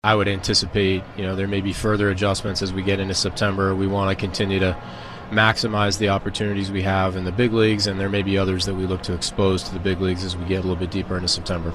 On The Ben Cherington Show on WCCS, the GM said the Pirates will add players today to get to the expanded roster limit of 28, but he will likely make other moves this month to get a look at some of their prospects currently playing for Indianapolis.